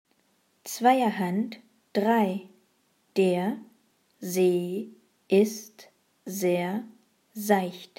Satz 1 Langsam
2er-1-langsam.mp3